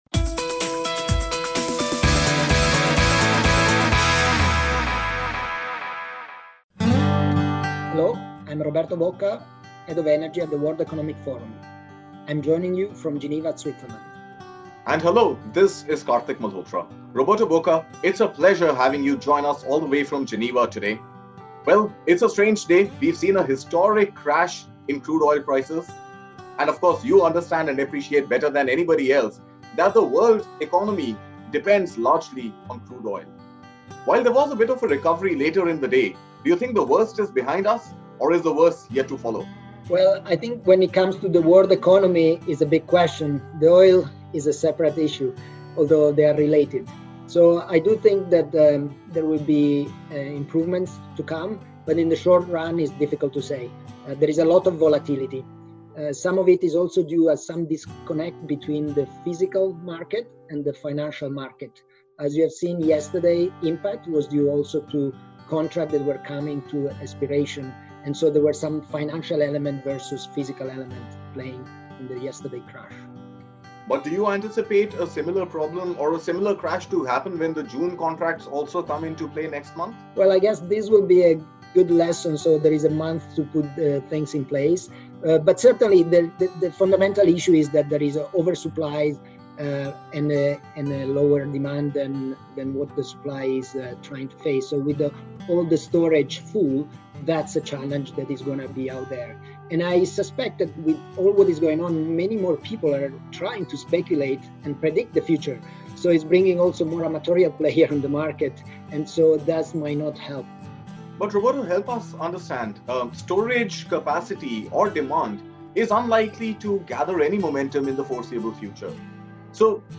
joining in from Geneva, Switzerland for this exclusive podcast